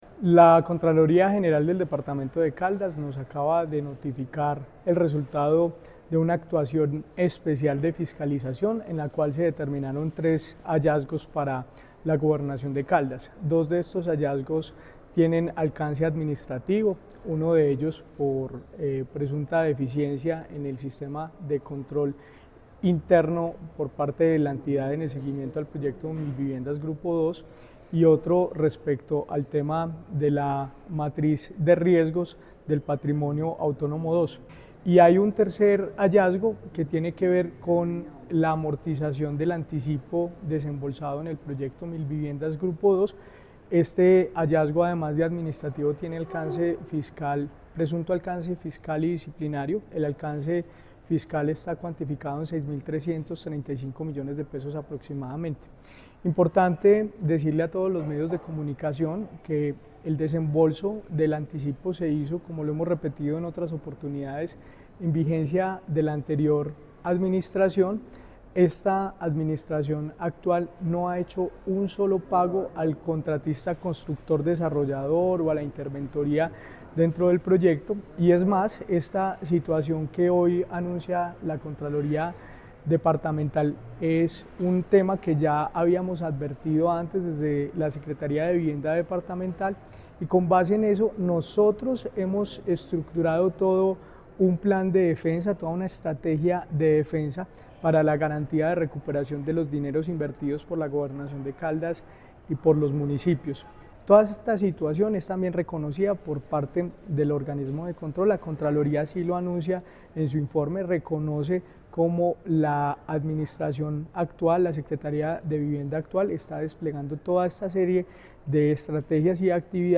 Secretario de Vivienda de Caldas, Jorge William Ruiz Ospina.